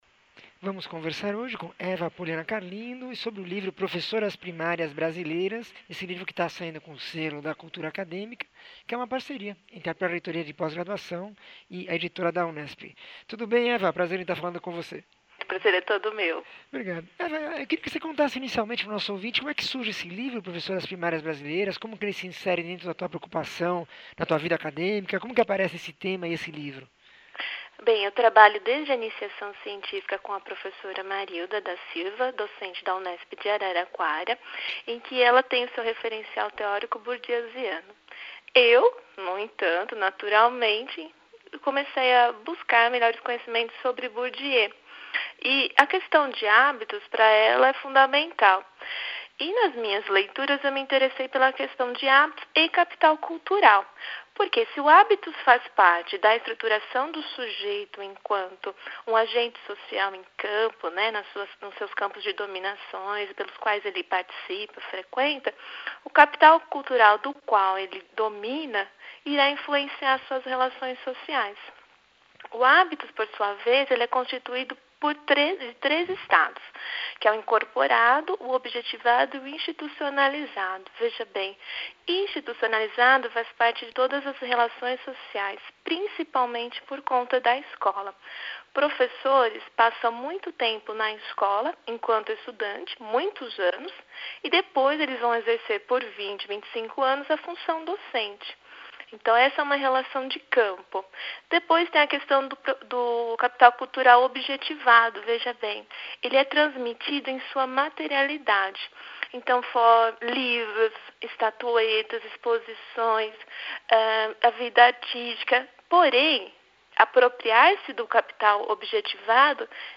entrevista 1460